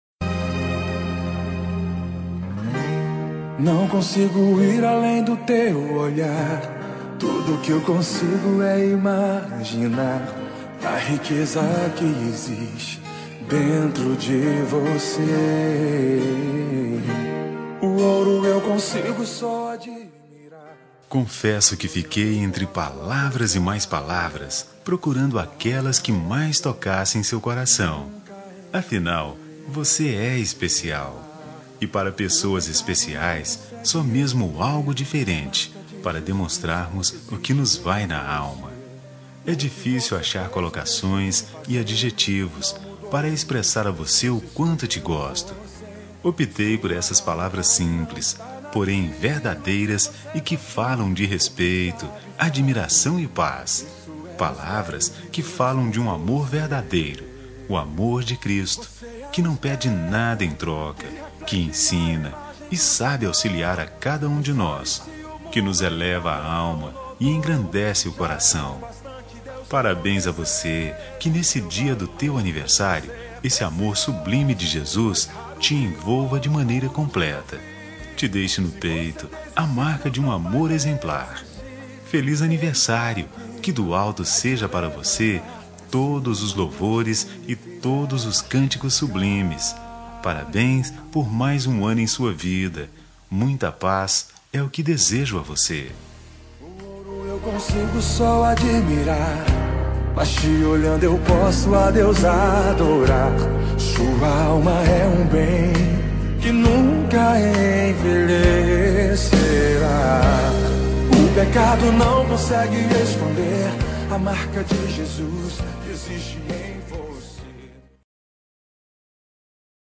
Aniversário Pessoa Especial Gospel – Voz Masculina – Cód: 6047